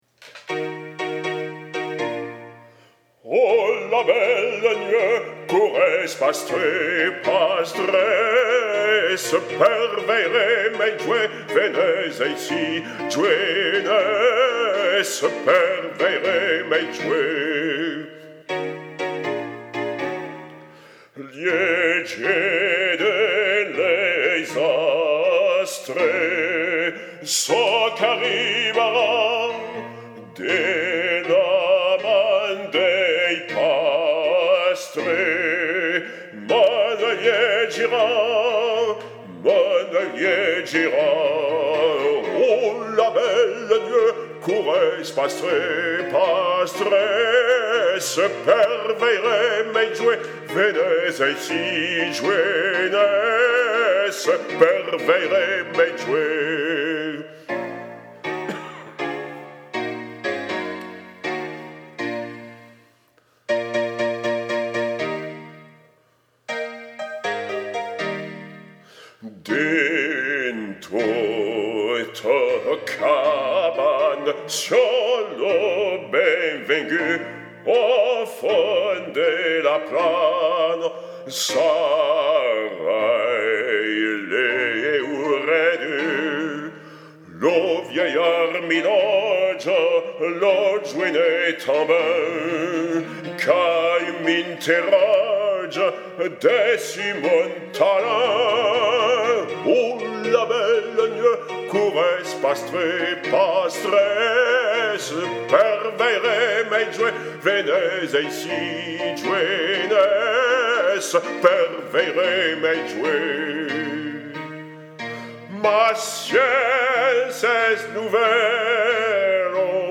Chants à 2 voix
Bello_nue-Voix-Piano.mp3